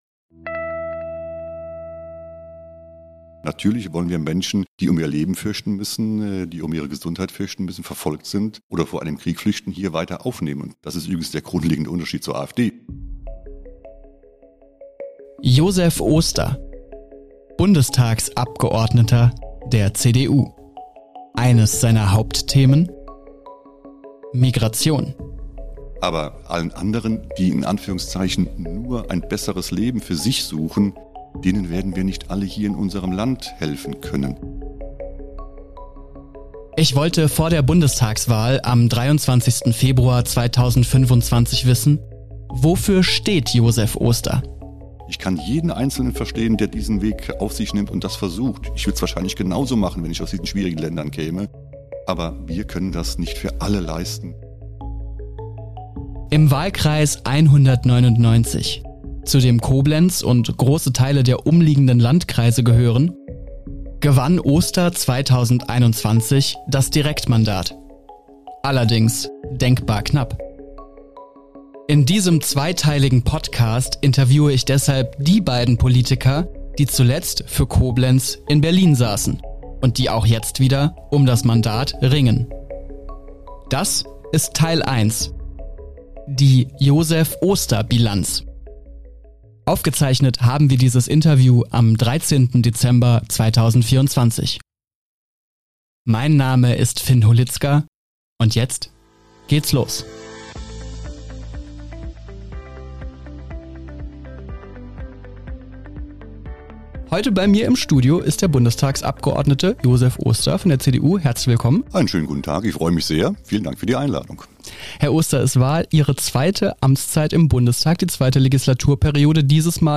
Bei RZInside hört ihr Interviews und Storys von Betroffenen, Experten und Reportern aus der Redaktion der Rhein-Zeitung.